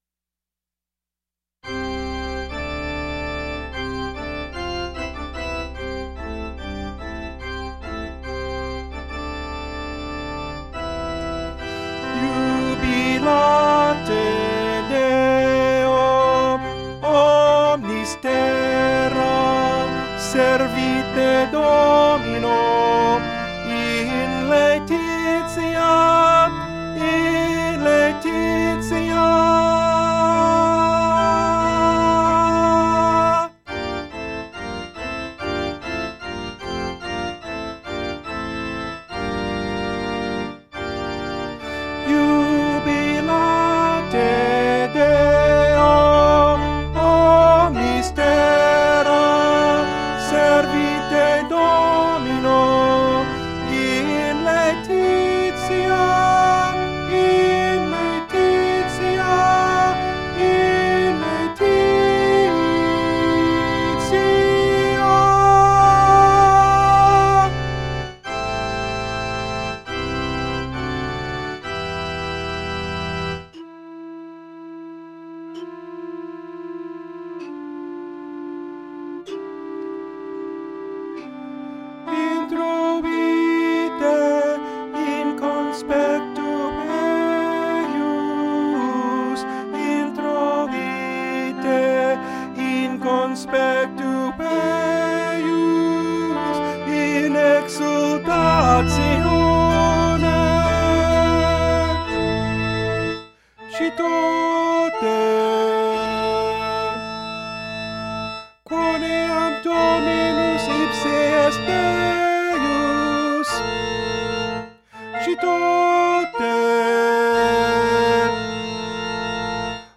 Tenor   Instrumental | Downloadable   Voice | Downloadable